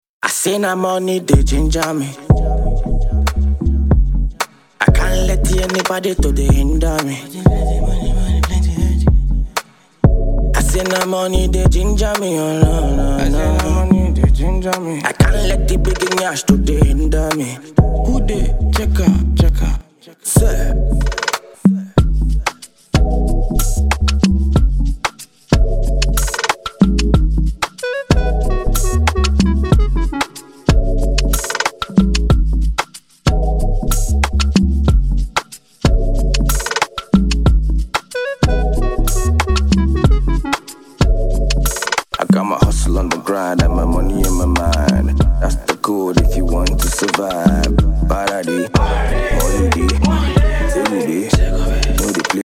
Here is an open verse,